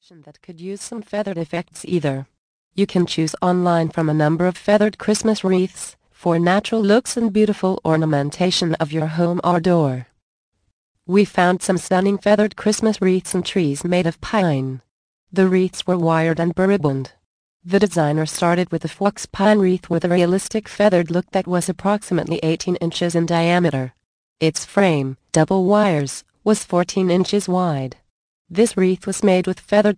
The Magic of Christmas audio book. Vol. 5 of 10 - 69min